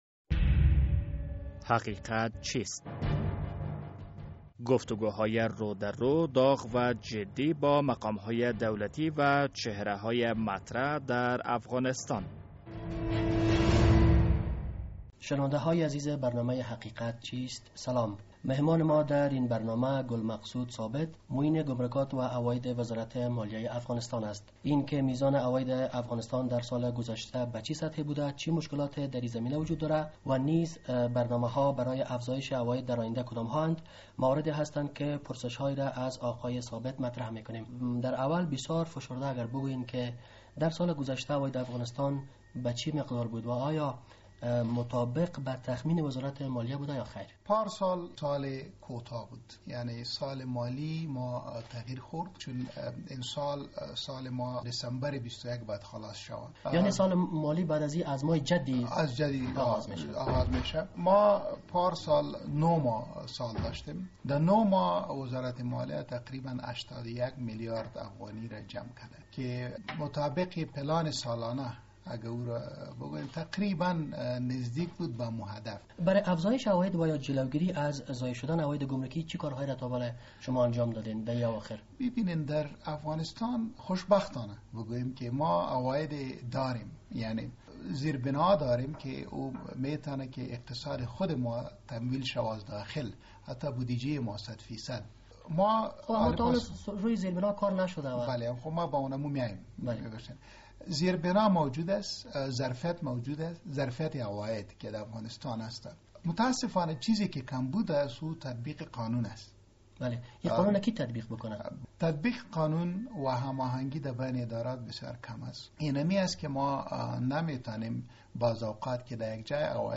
در این برنامه مهمان ما گل مقصود ثابت معین گمرکات و عواید وزارت مالیه افغانستان است.